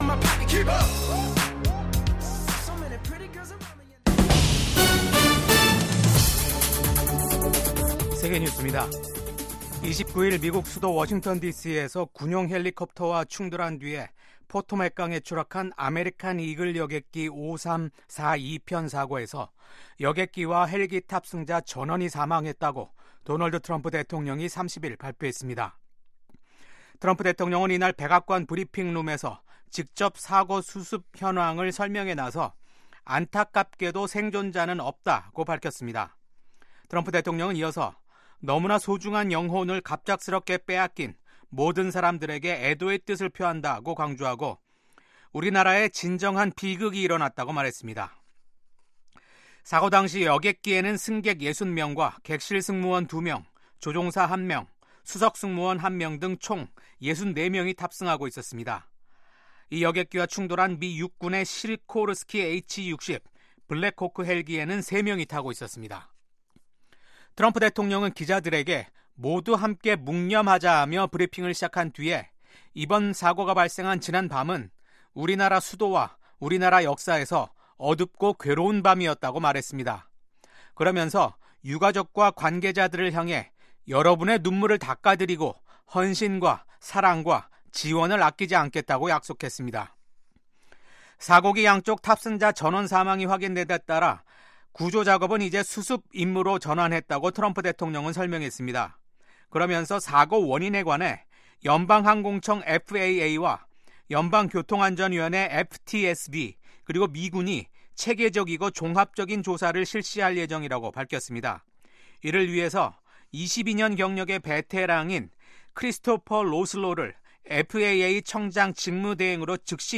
VOA 한국어 아침 뉴스 프로그램 '워싱턴 뉴스 광장'입니다. 미국 의회에서 한반도 문제를 담당할 소위원회 구성이 완료된 가운데 한반도 정책에는 변화가 없을 거란 전망이 나옵니다. 도널드 트럼프 미국 대통령이 동맹과의 미사일 방어를 강화하라고 지시한 데 대해 전문가들은 미한 통합미사일 방어 구축 의지를 확인한 것으로 해석했습니다.